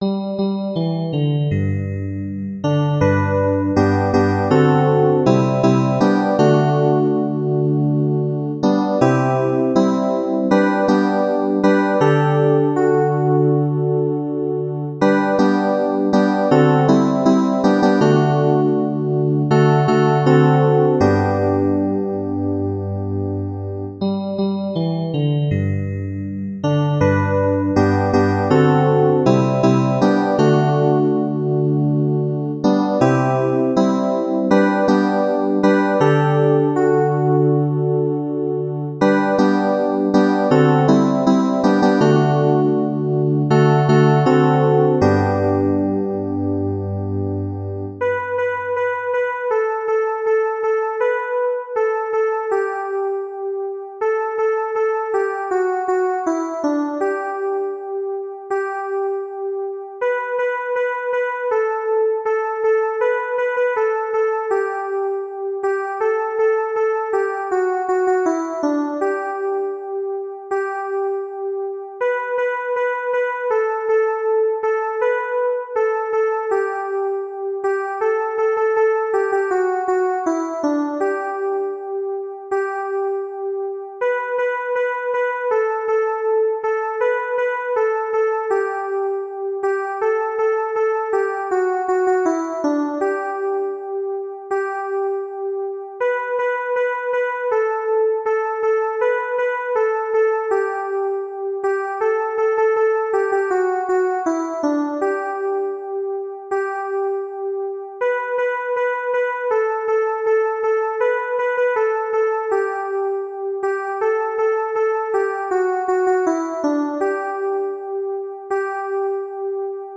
Chants de Requiem Téléchargé par